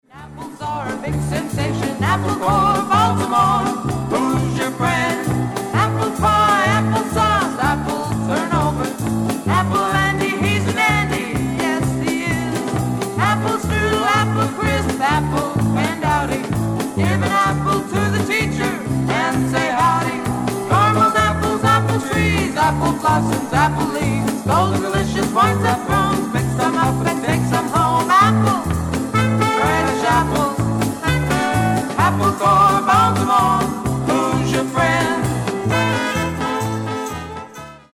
SOFT ROCK / PSYCHEDELIC POP